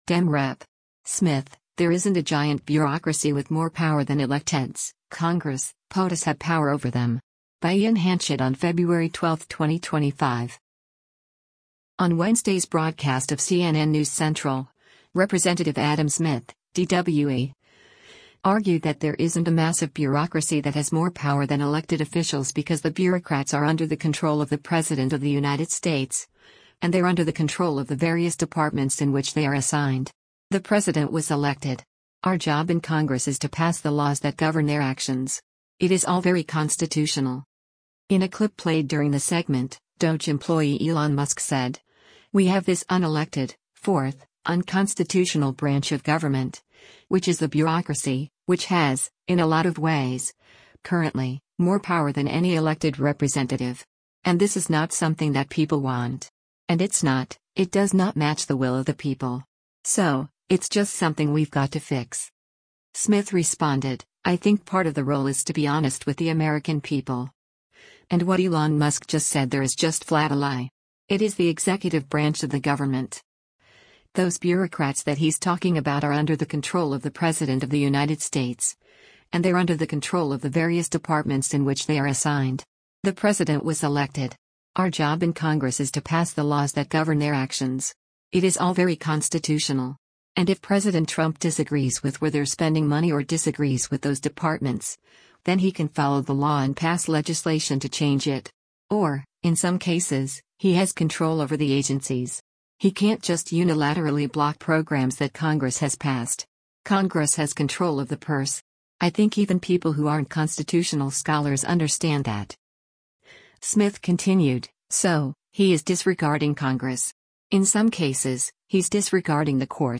On Wednesday’s broadcast of “CNN News Central,” Rep. Adam Smith (D-WA) argued that there isn’t a massive bureaucracy that has more power than elected officials because the bureaucrats “are under the control of the president of the United States, and they’re under the control of the various departments in which they are assigned. The president was elected. Our job in Congress is to pass the laws that govern their actions. It is all very constitutional.”